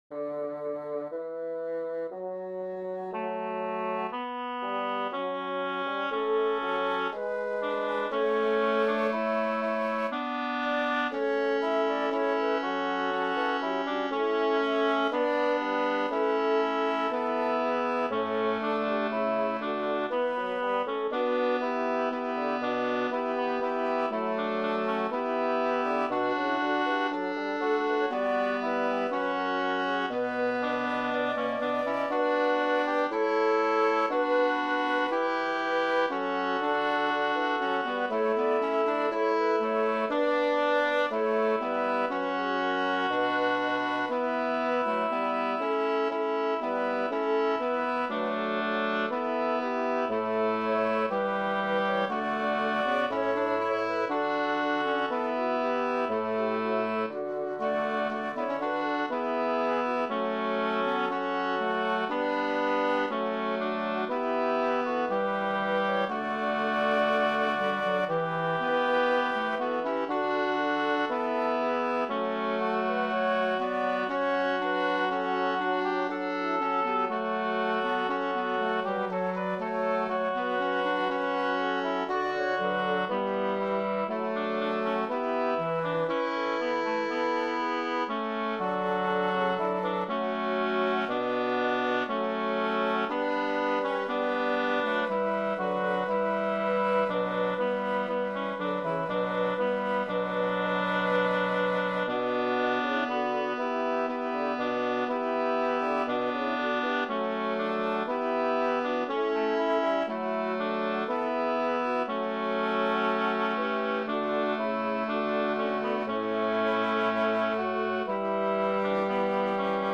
Übehilfen für das Erlernen von Liedern
resinarius-das-vater-unser-tutti.mp3